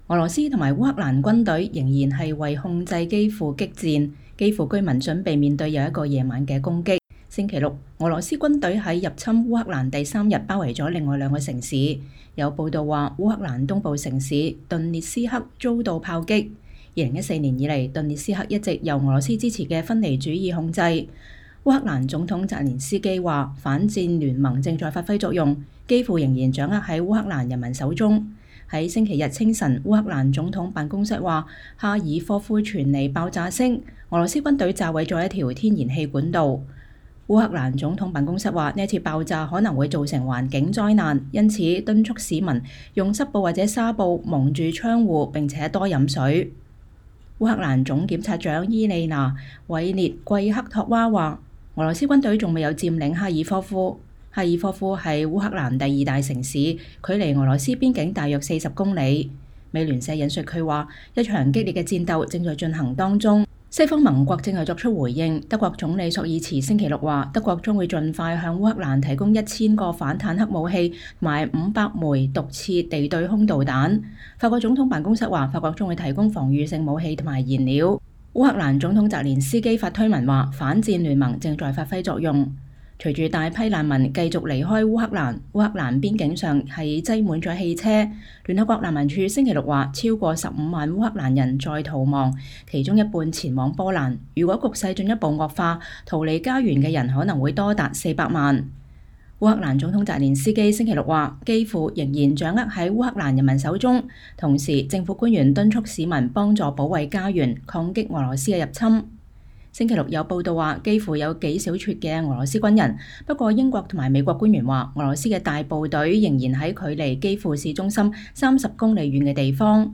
烏克蘭總統澤連斯基在基輔街上錄製的視頻中說，烏克蘭軍隊擊退了敵人的進攻。